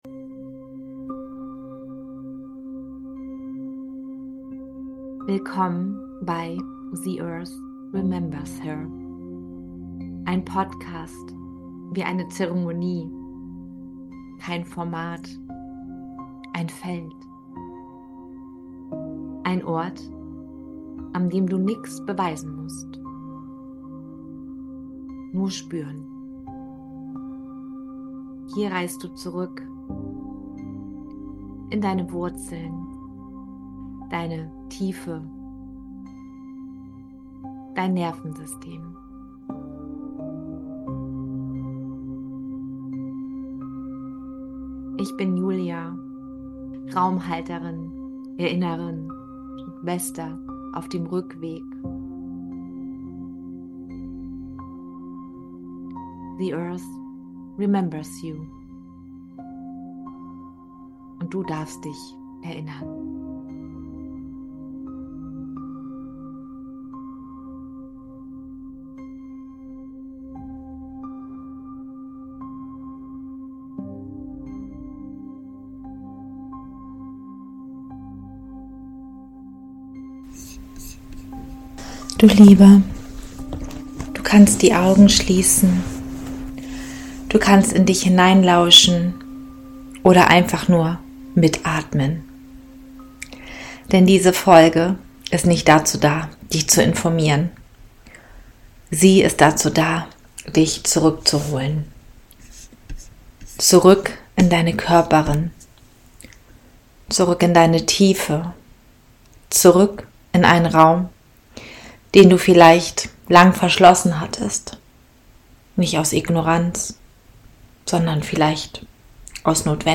Diese Folge ist kein Gespräch. Sie ist ein inneres Ritual.